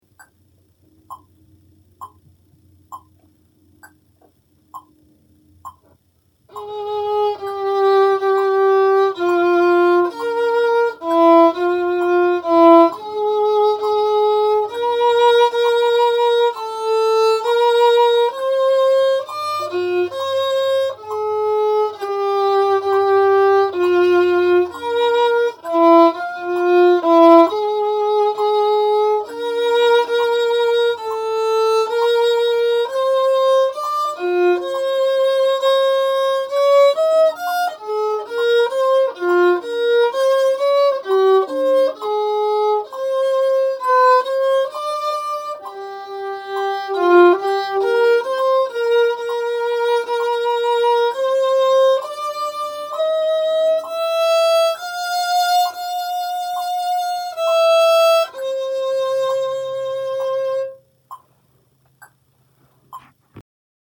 Chorale No. 19 Tenor